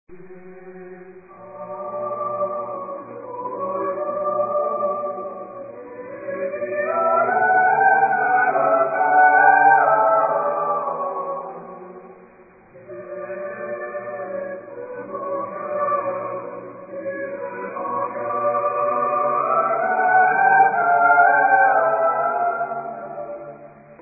SATB (4 voices mixed) ; Full score.
Partsong.
Romantic.